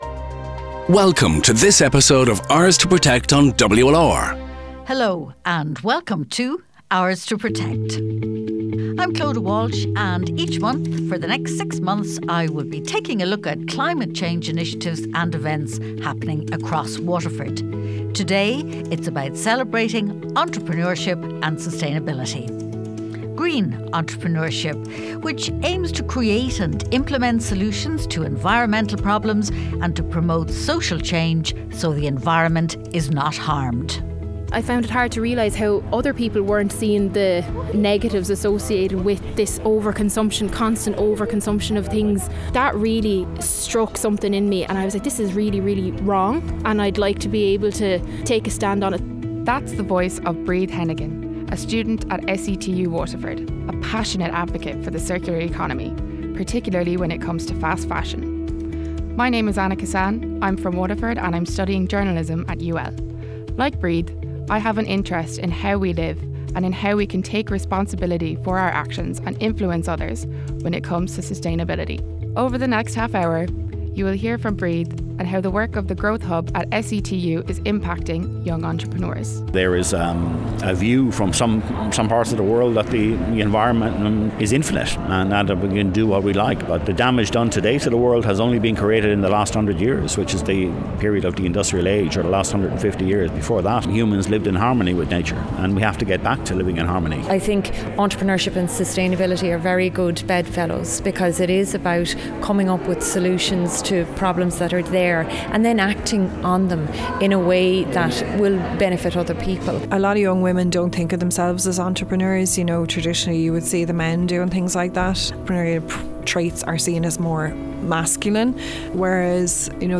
In this programme, we hear from one student whose passion is sustainability and the circular economy, along with her mentors and businesspeople who have paved the way in the green economy.